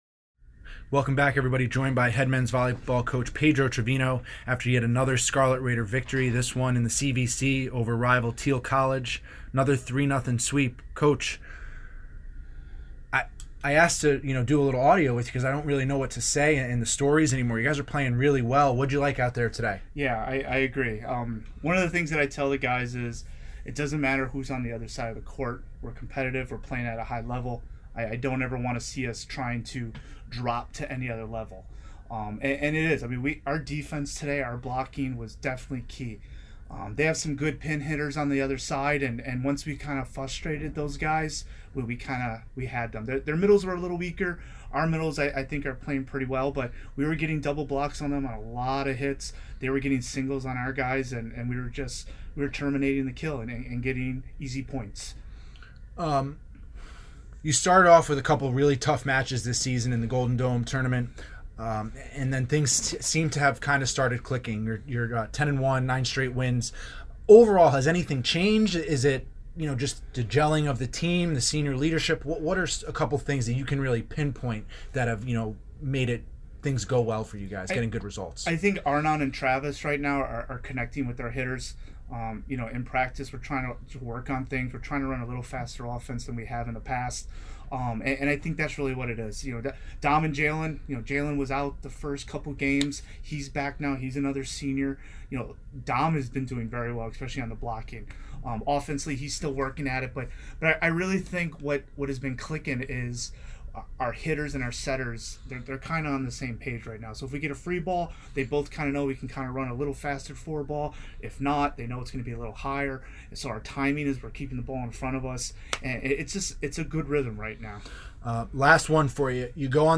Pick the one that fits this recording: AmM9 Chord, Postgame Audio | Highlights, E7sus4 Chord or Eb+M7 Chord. Postgame Audio | Highlights